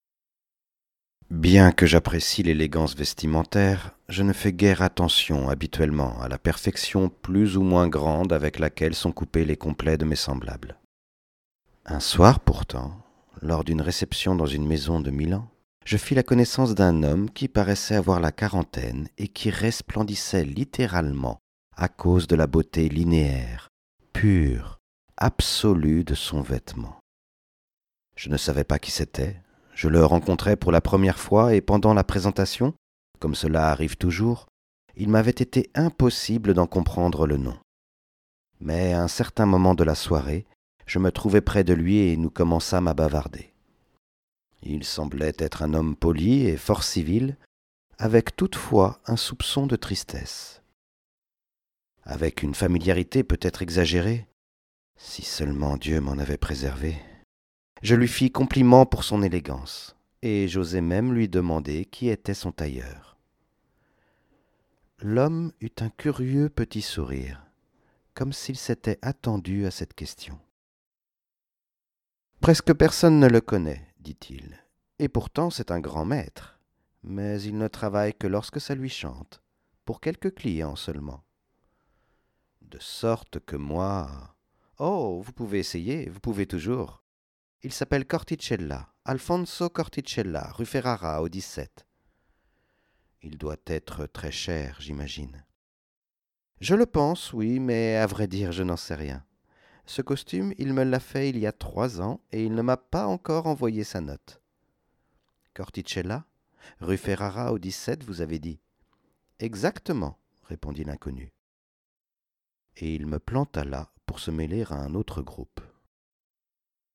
- Baryton